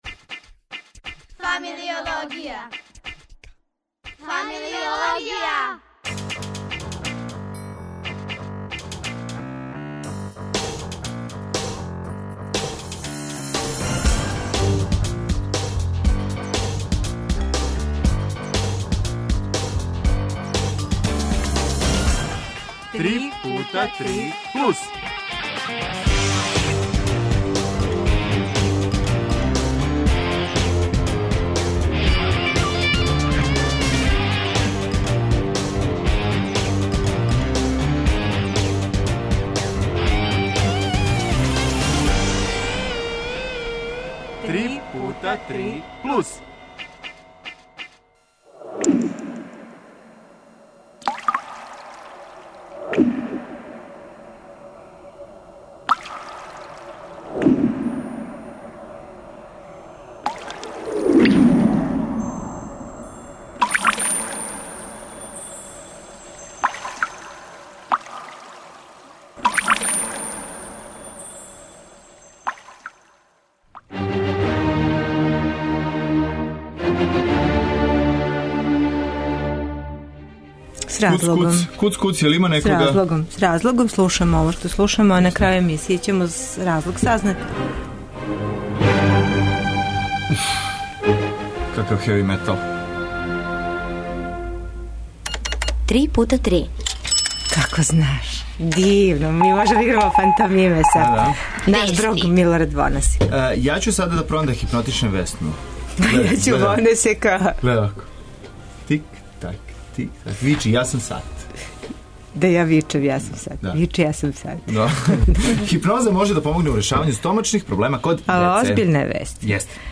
А плешу и наши гости у студију, чланови плесне трупе Celtic Rhythm, пред Фестивал игре...